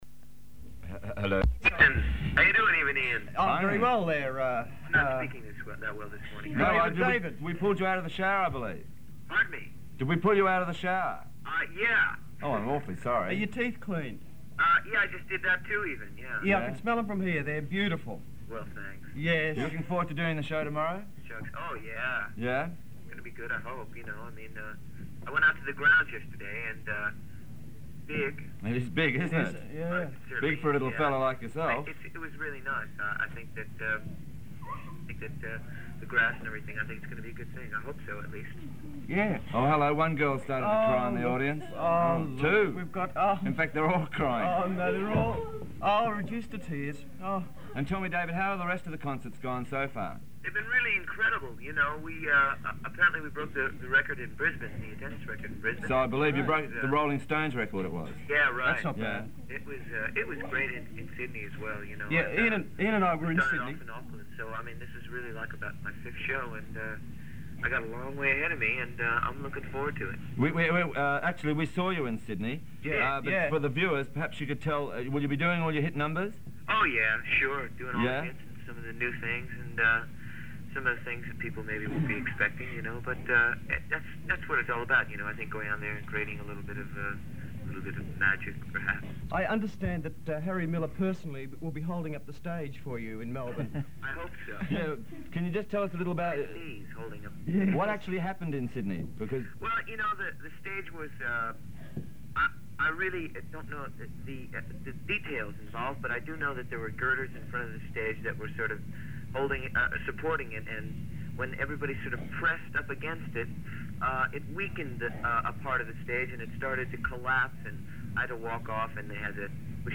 The Do It TV show was from Melbourne, Australia.